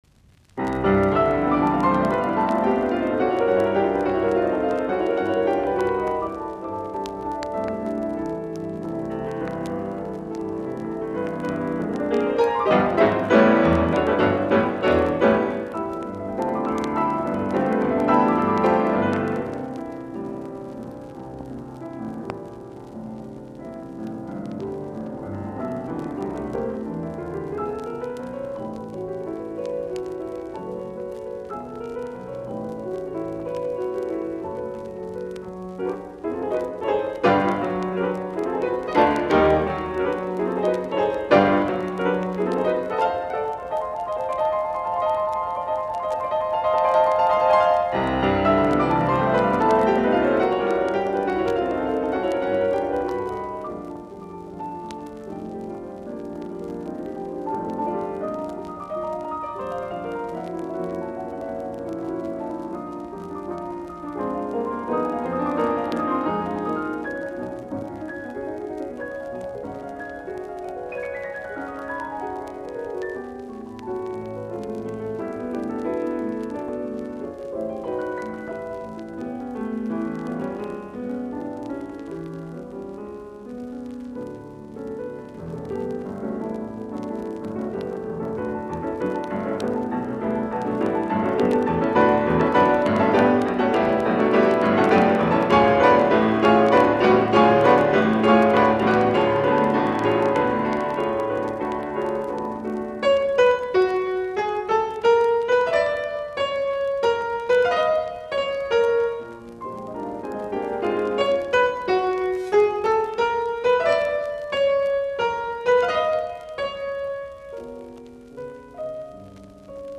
musiikkiäänite
piano.
Soitinnus : Pianot (2).